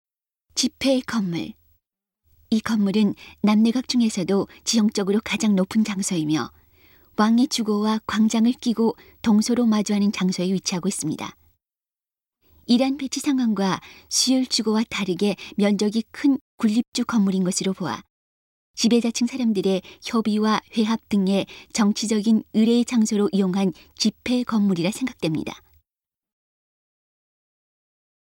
음성 가이드 이전 페이지 다음 페이지 휴대전화 가이드 처음으로 (C)YOSHINOGARI HISTORICAL PARK